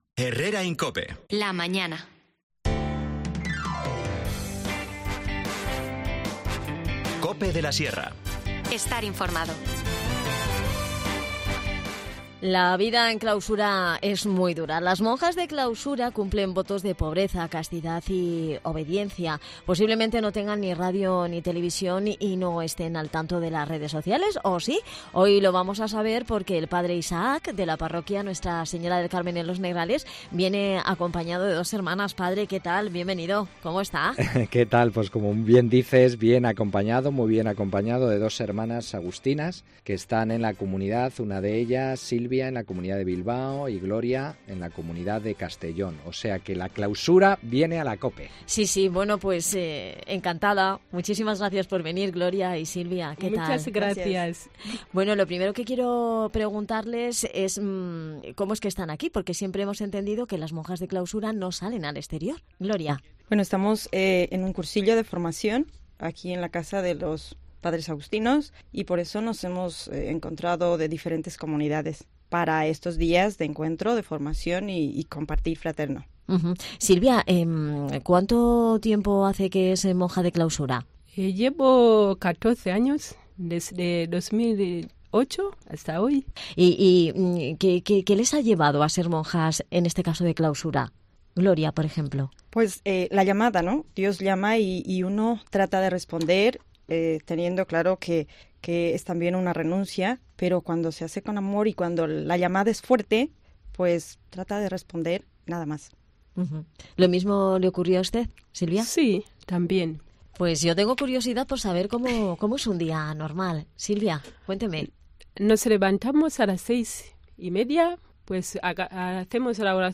han venido hasta nuestros estudios para descubrirnos cómo es su día a día, su forma de vida.